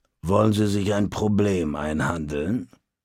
Malegenericghoul_dialoguemsmini_greeting_000c9cd3.ogg